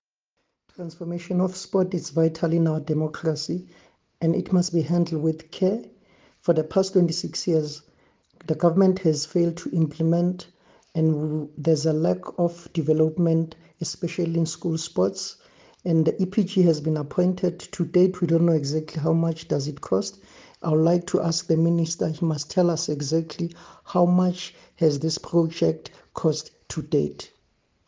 Please find an attached soundbite by in
English Tsepo Mhlongo MP, DA Shadow Minister of Sports, Arts & Culture